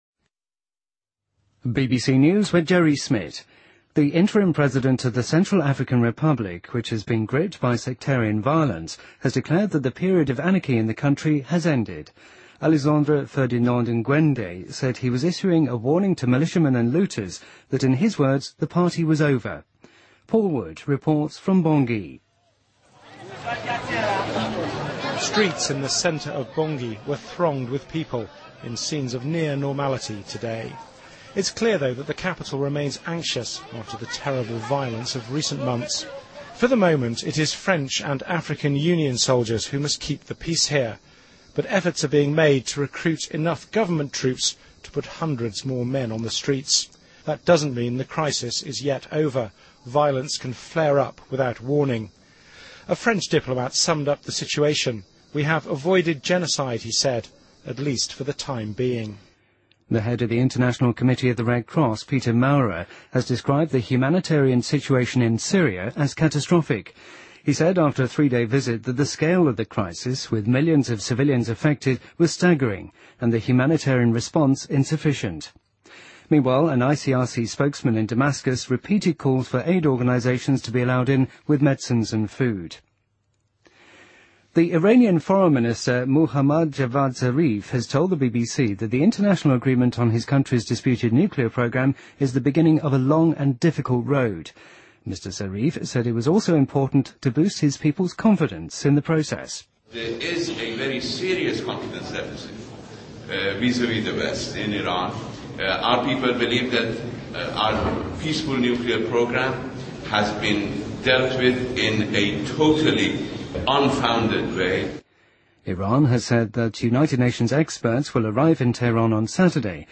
BBC news,2014-01-14